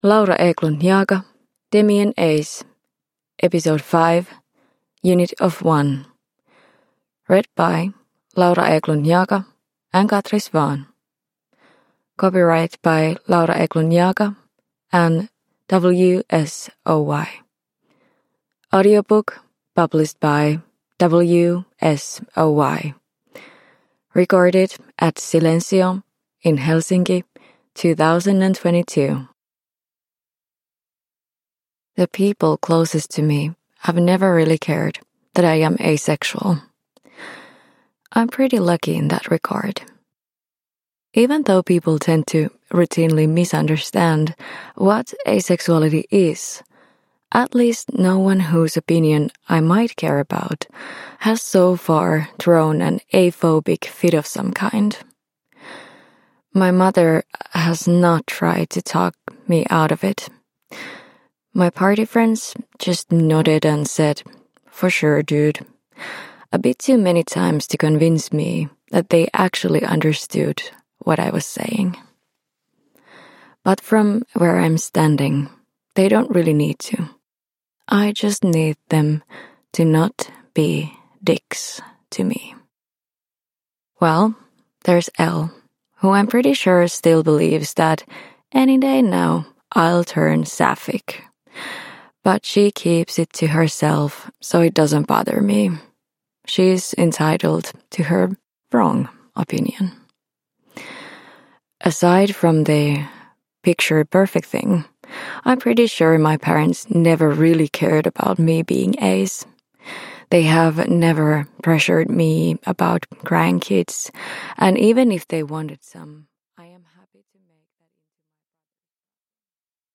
Demi and Ace 5: Unit of One – Ljudbok – Laddas ner